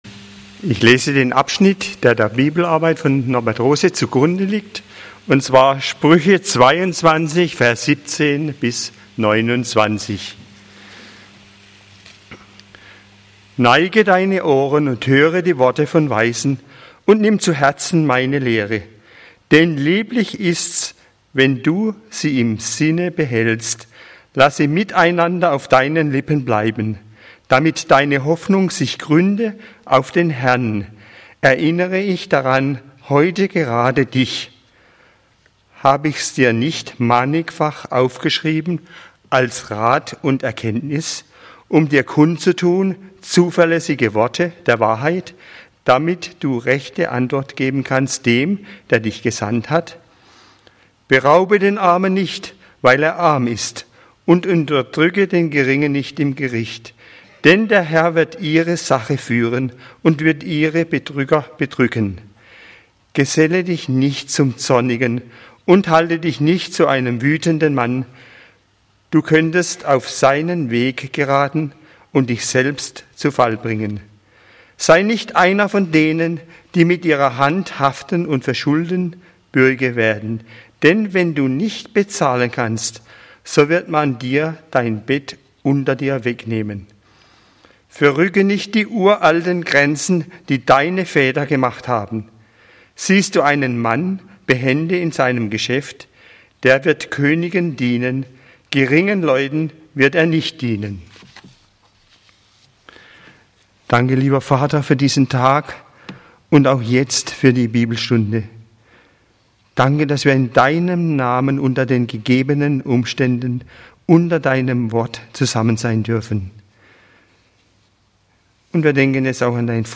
Zuverlässige Worte der Wahrheit (Spr. 22, 17-29) - Bibelstunde